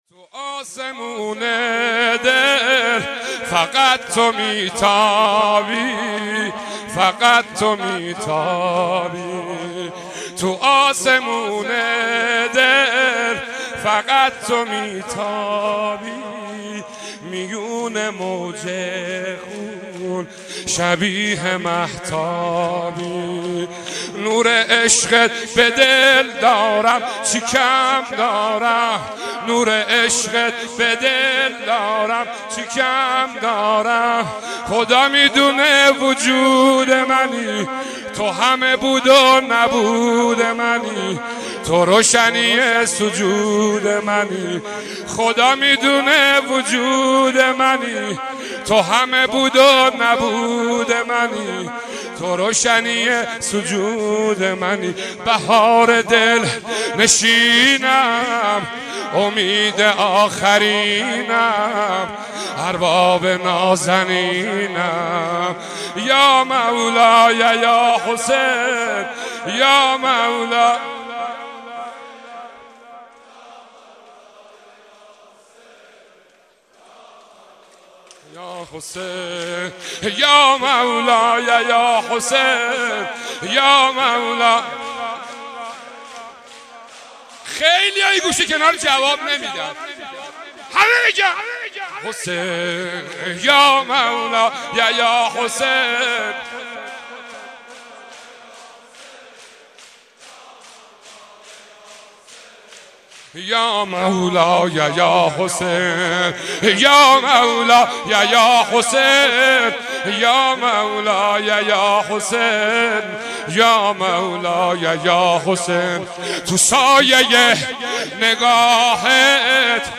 مناسبت : شب هشتم محرم
مداح : حاج منصور ارضی قالب : شور